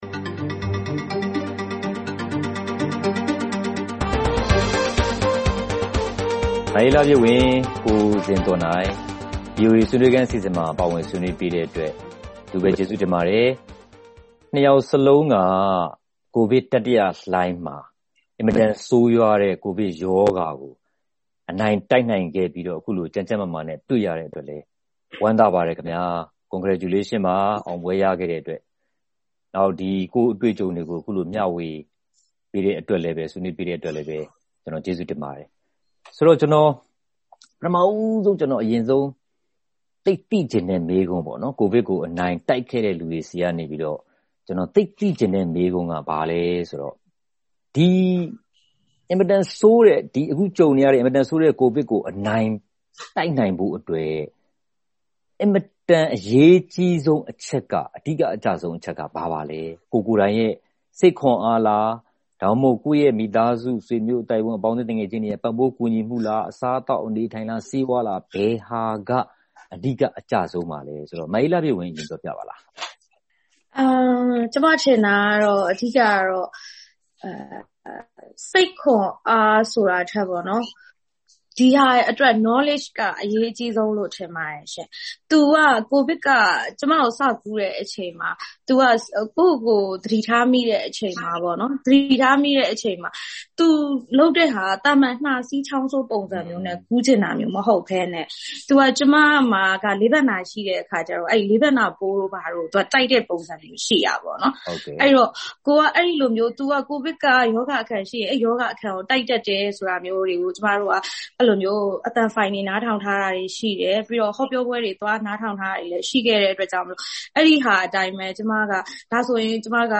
ကိုဗစ်ကို ဘယ်လိုအနိုင်တိုက်မလဲ ... (ဆွေးနွေးခန်း)